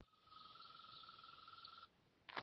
Zemesvēzis, Gryllotalpa gryllotalpa
СтатусСлышен голос, крики